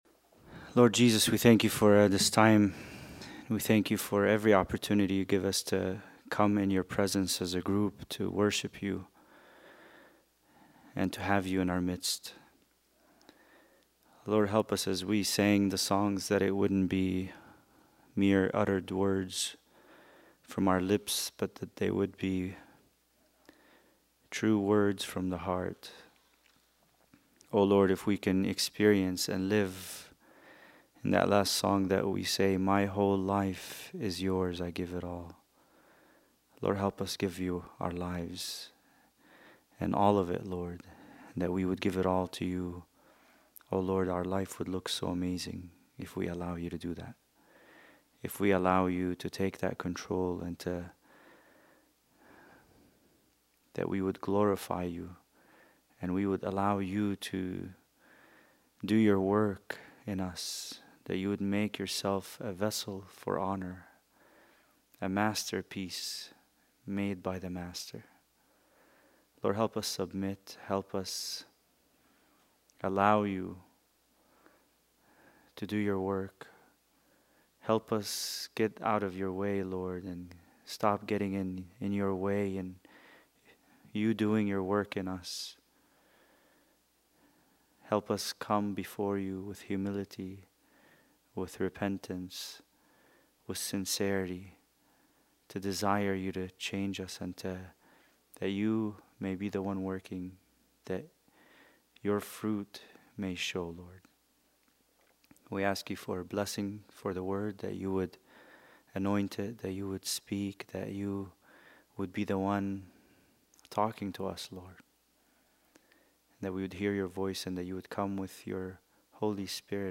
Bible Study: Ephesians 2:1-3